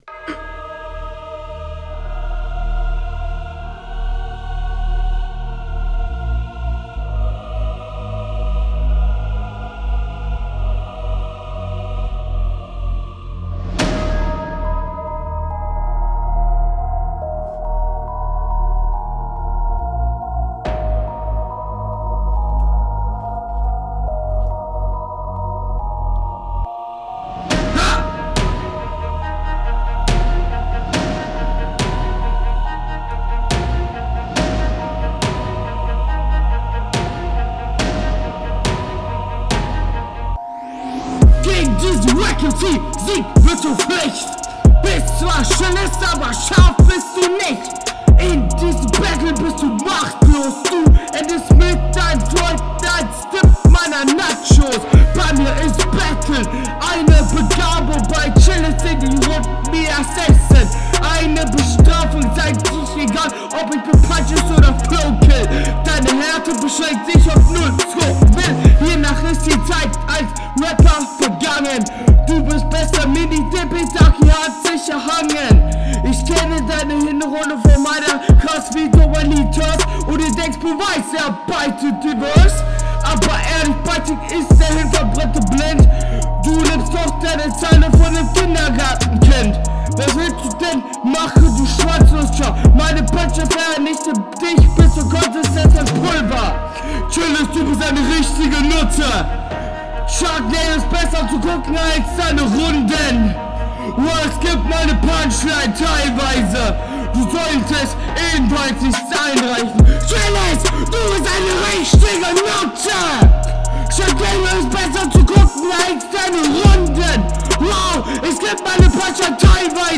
Pegel dein Mic ein bissche viel wenig runter